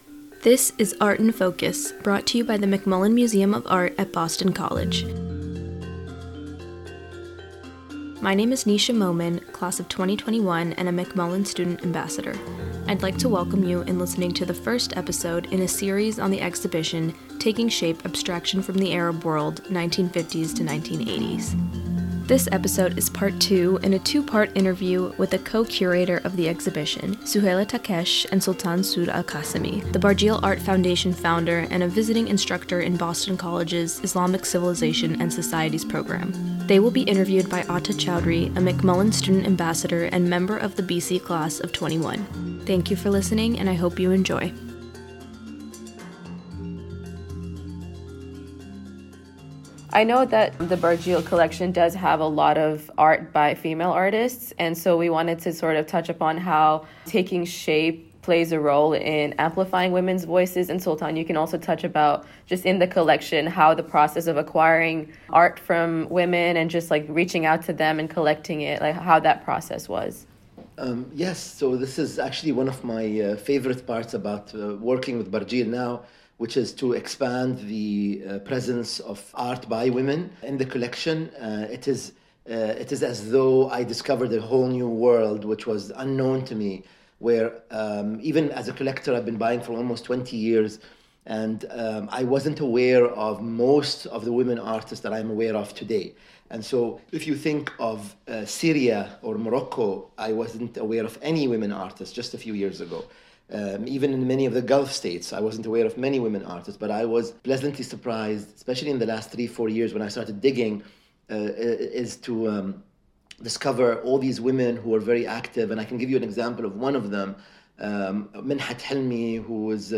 Interview
The McMullen Student Ambassadors are pleased to present Art in Focus, featuring an informal discussion between professors from various academic departments at Boston College.